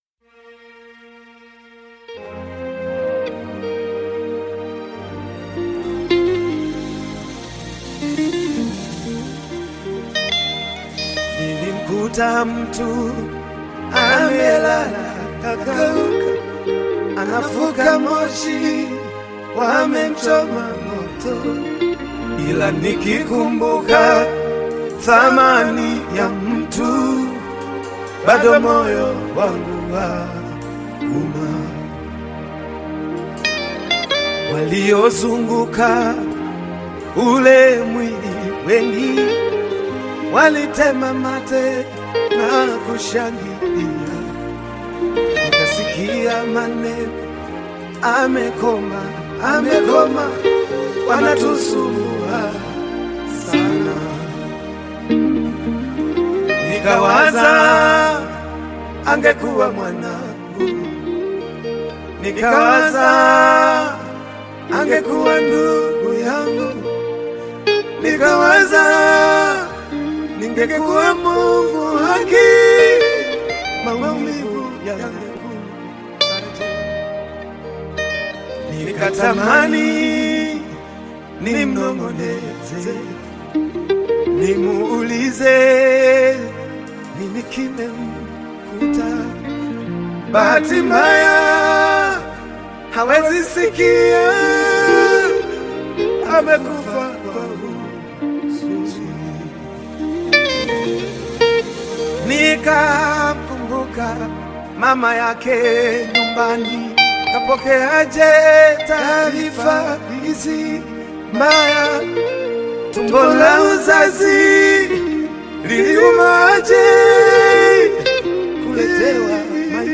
Tanzanian gospel artist, singer, and songwriter
Gospel song